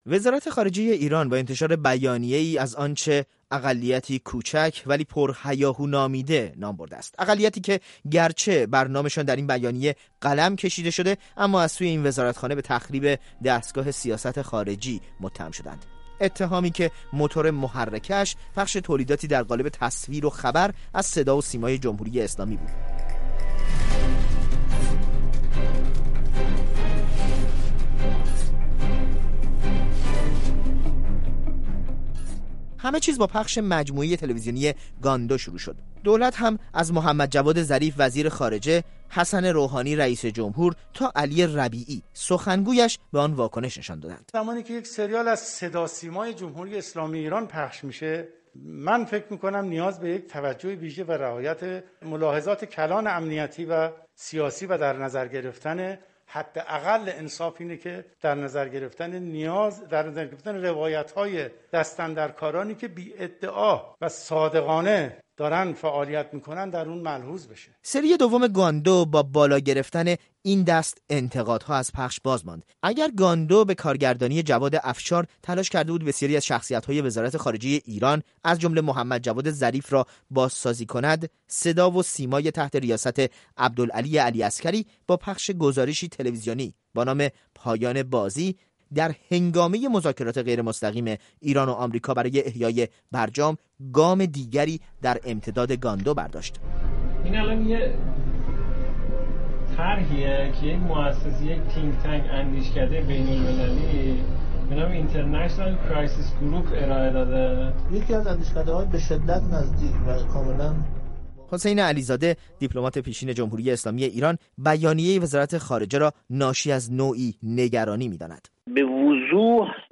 گزارش می‌دهد